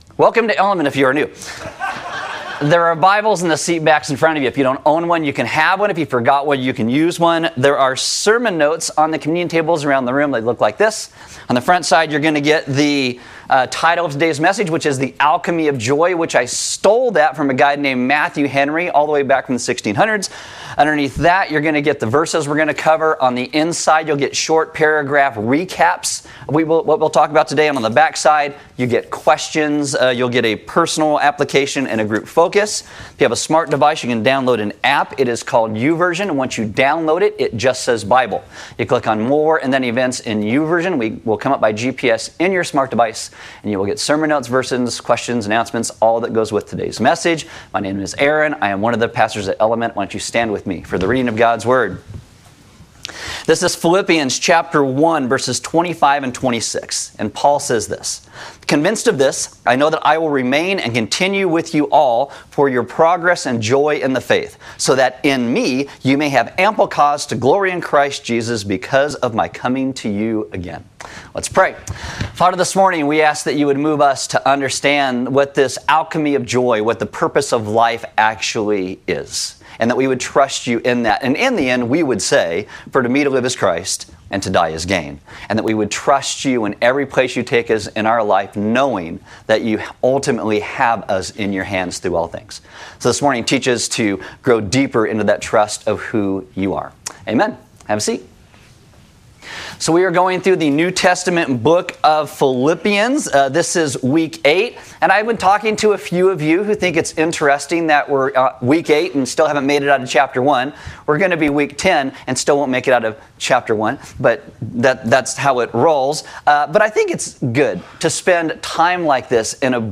Service Audio Paul writes the letter to the Philippian church while chained to guards in Rome.